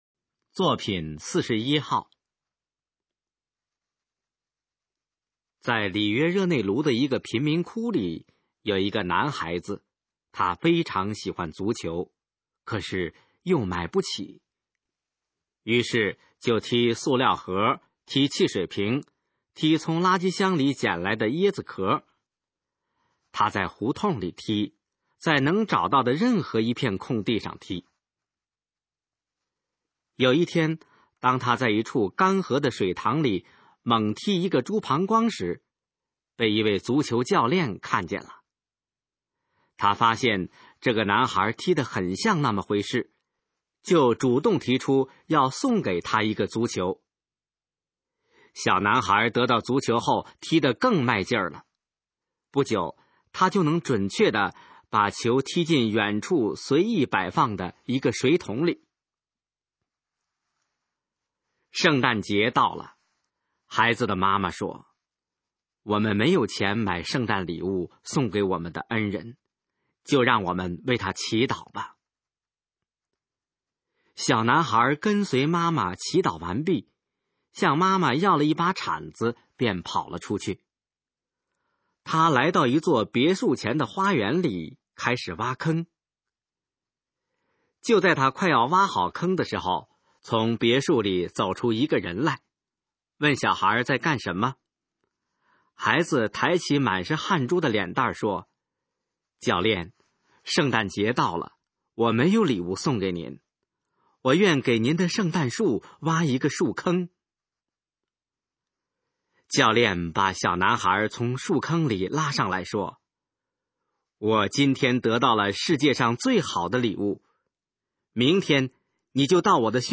《天才的造就》示范朗读_水平测试（等级考试）用60篇朗读作品范读